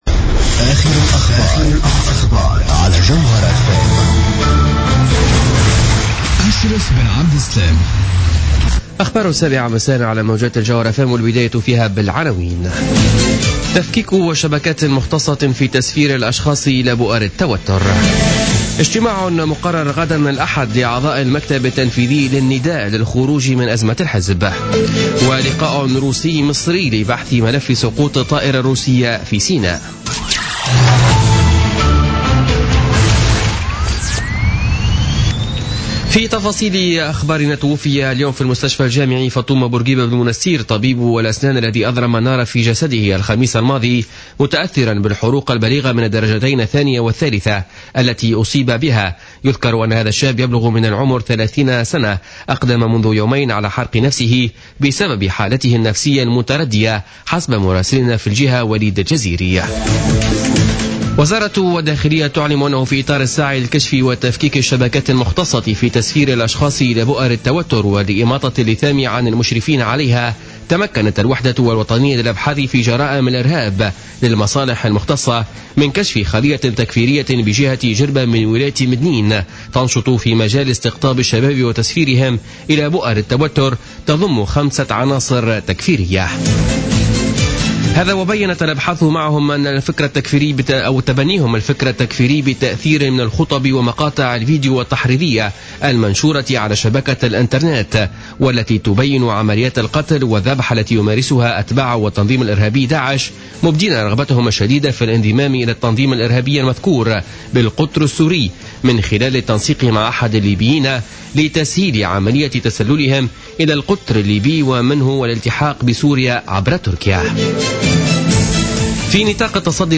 نشرة أخبار السابعة مساء ليوم السبت 07 نوفمبر 2015